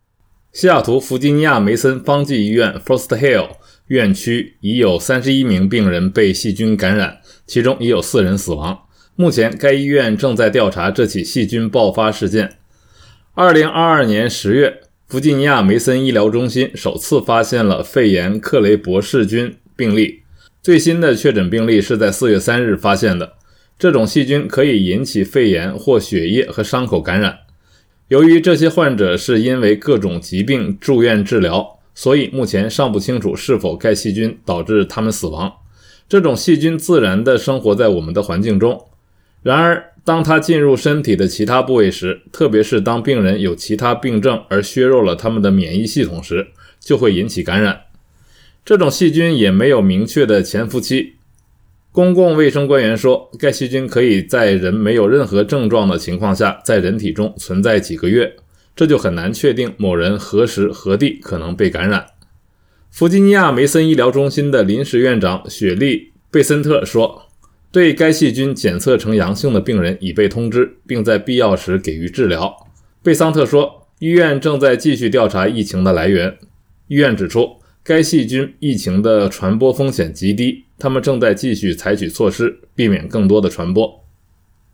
每日新聞